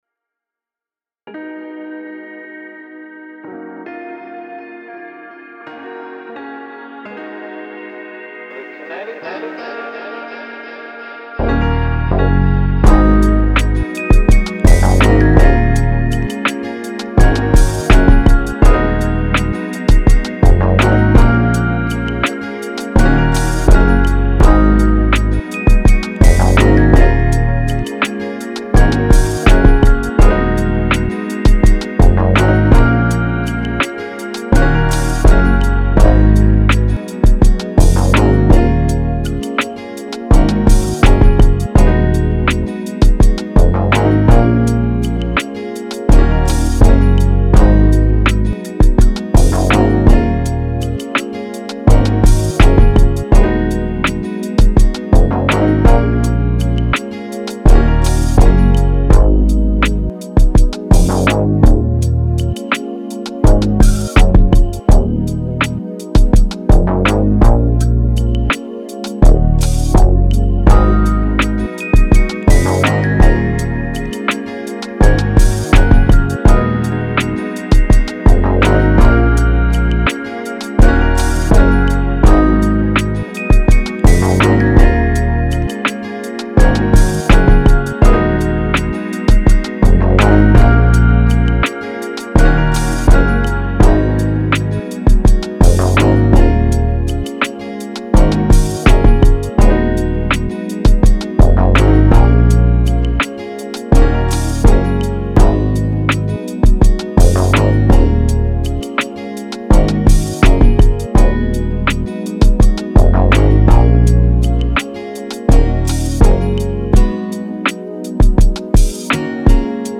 Soul, R&B, Lofi
Eb Min